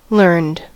learned: Wikimedia Commons US English Pronunciations
En-us-learned.WAV